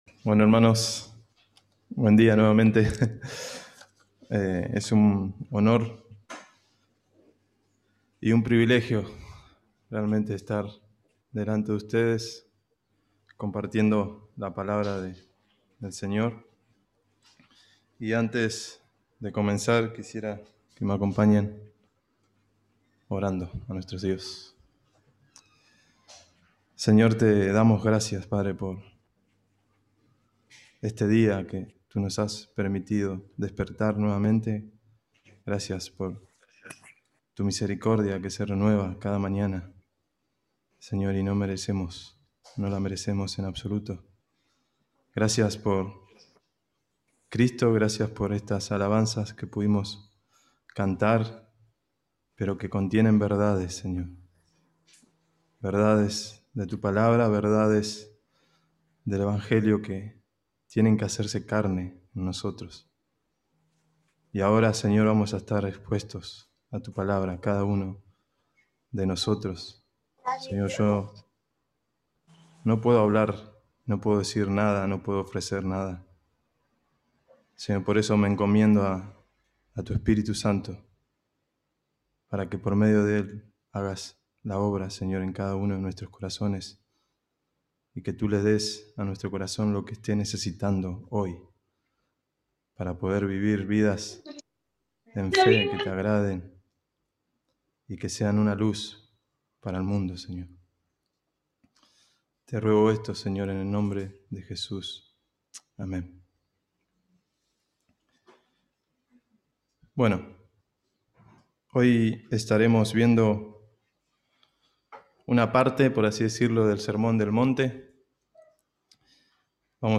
diciembre 27, 2020 Sermón ¿Dónde está tu tesoro?